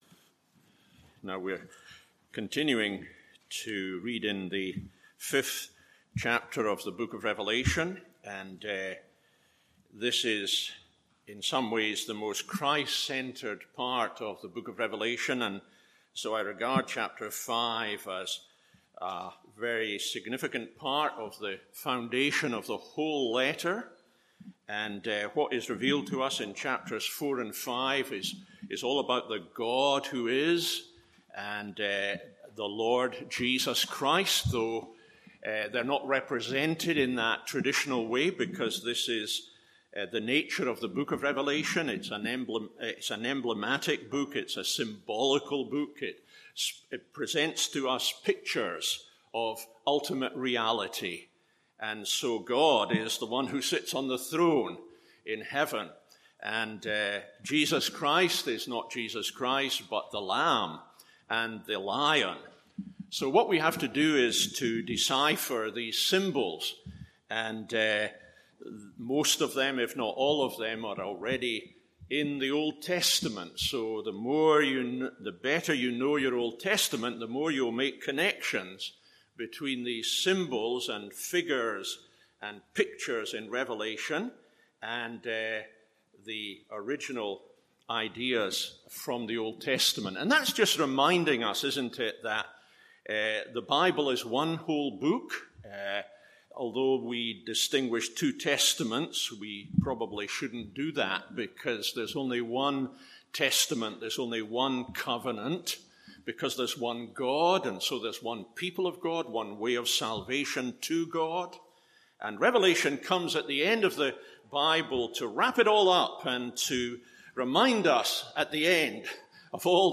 MORNING SERVICE Rev 5:6-14…